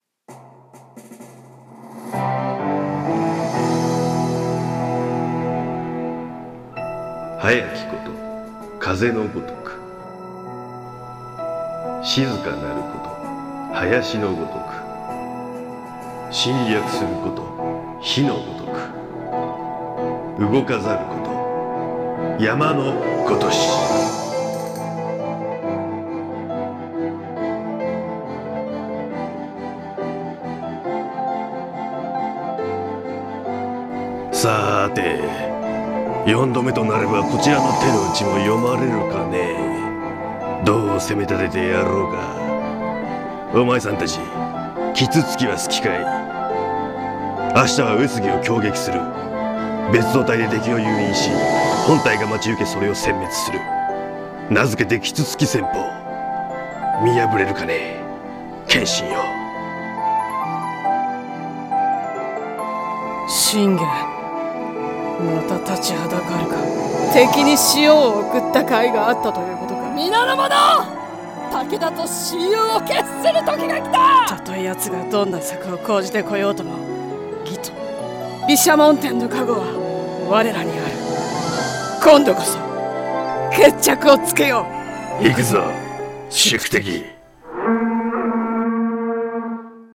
【声劇】風林火山 信玄 VS 謙信